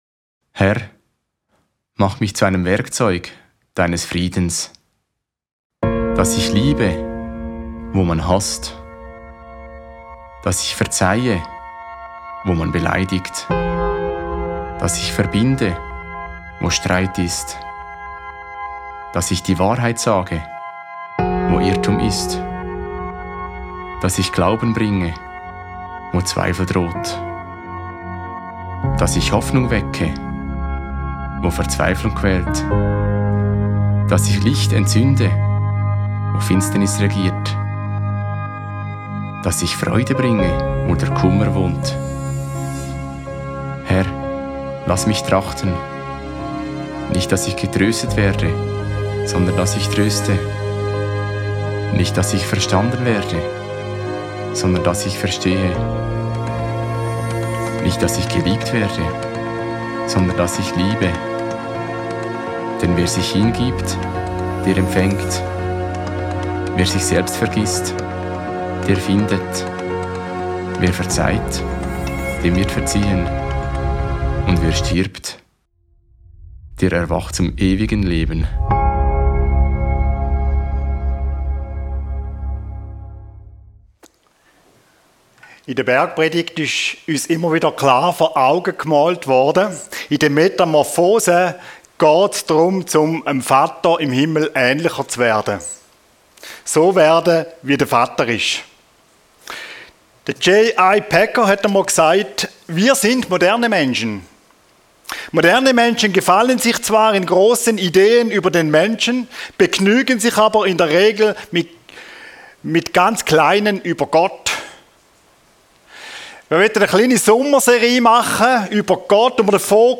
Die Herrlichkeit Gottes - seetal chile Predigten